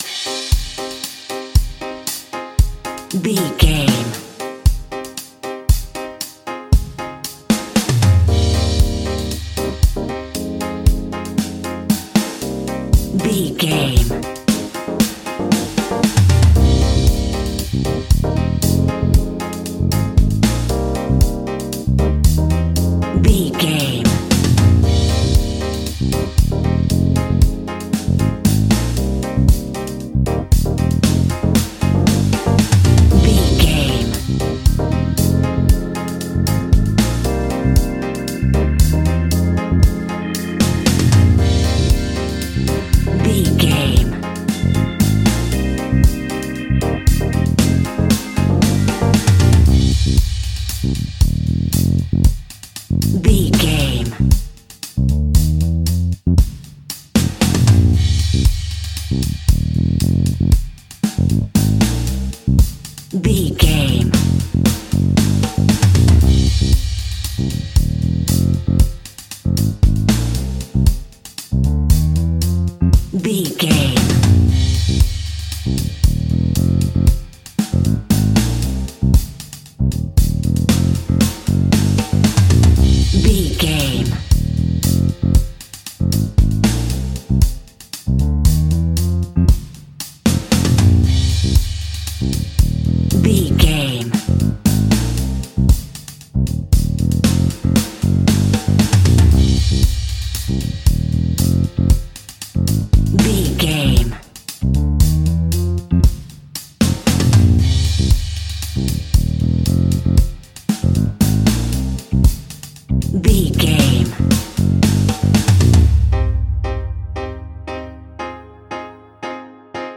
Aeolian/Minor
dub
laid back
chilled
off beat
drums
skank guitar
hammond organ
transistor guitar
percussion
horns